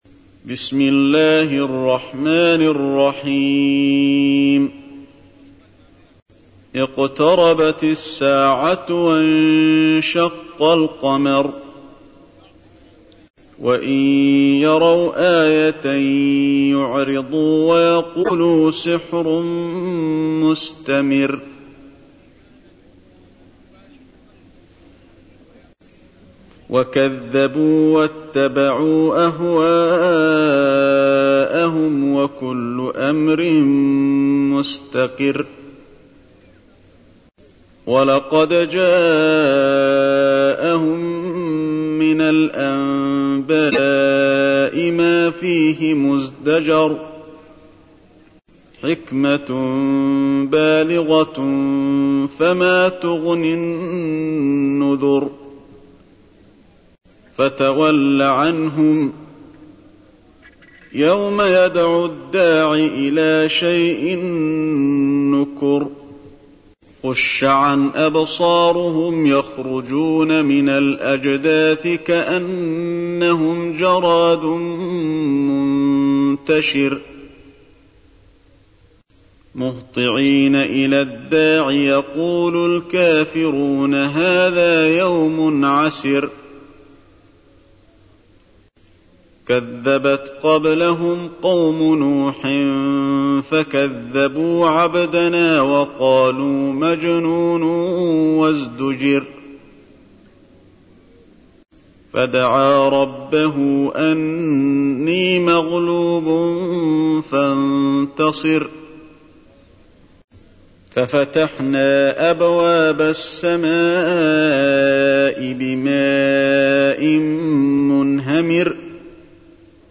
تحميل : 54. سورة القمر / القارئ علي الحذيفي / القرآن الكريم / موقع يا حسين